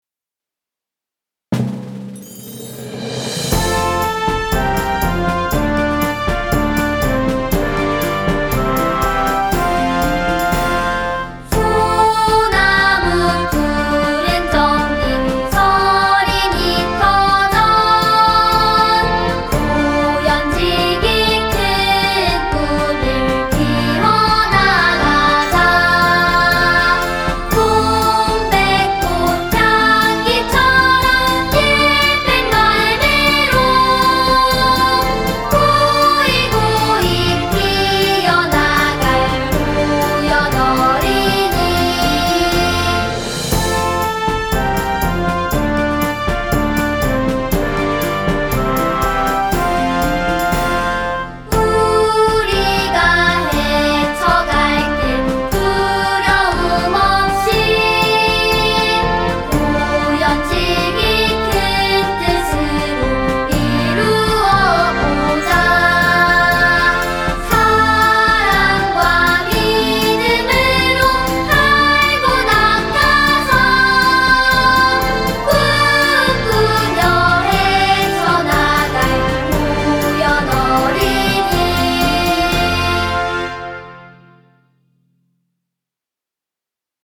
호연초등학교 교가 음원 :울산교육디지털박물관
호연초등학교의 교가 음원으로 김진덕 작사, 작곡이다.